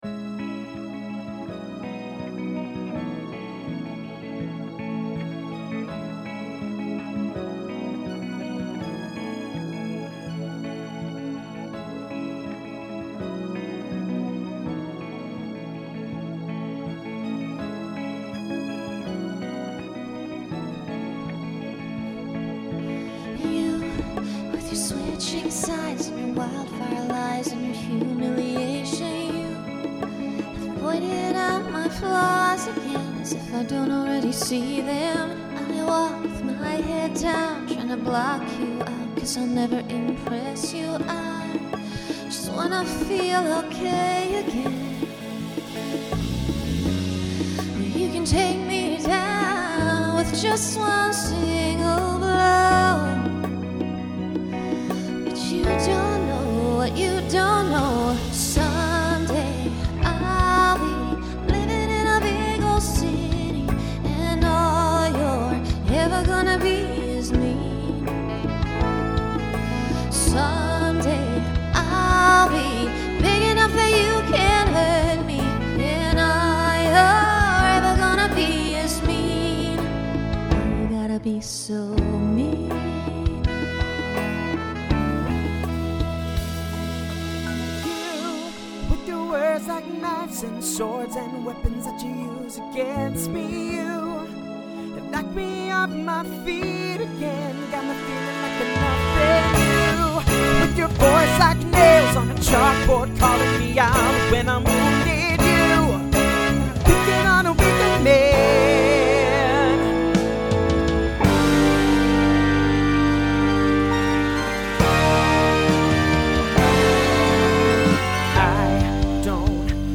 Pop/Dance , Rock Instrumental combo
Transition Voicing Mixed